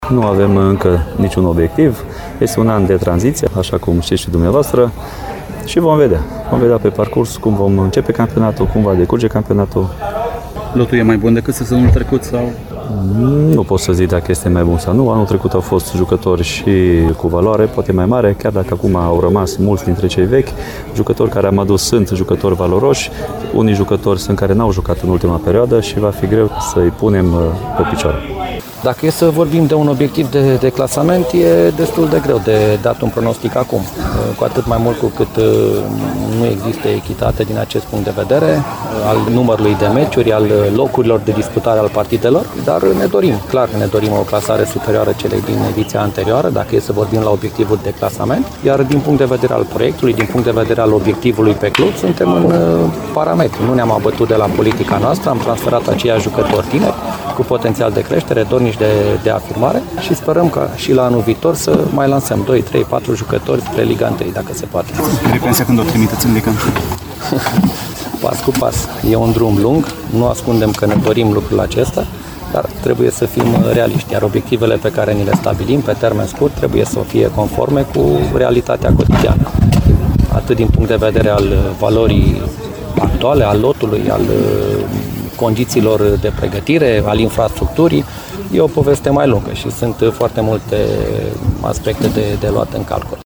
Este întrebarea pe care a lansat-o Divizia Sport Radio Timișoara, cu ocazia amicalului direct dintre Ripensia și SSU Politehnica, desfășurat cu o săptămână înainte de startul campionatului.